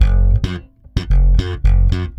-AL DISCO G.wav